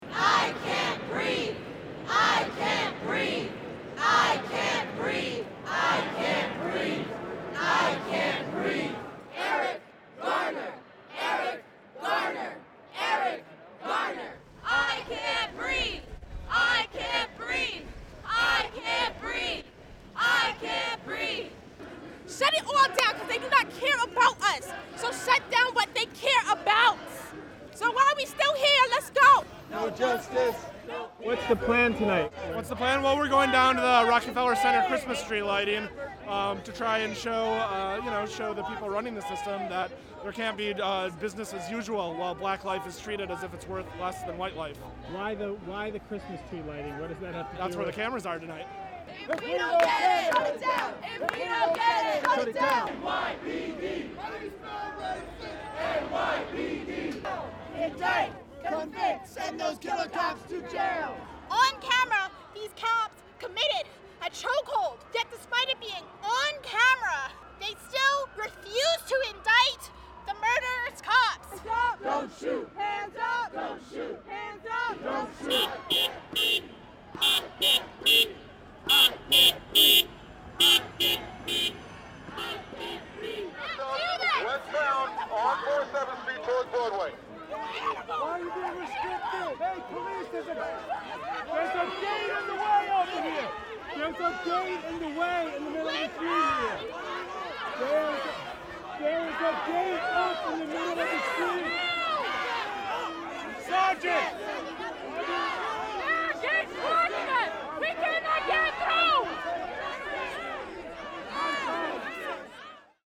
Demonstrators pack the streets of Midtown Manhattan.
The protest began at 4:30pm with a "die-in" staged at Grand Central Terminal, in which about 20 people lay down on the floor in the middle of the commuter hub.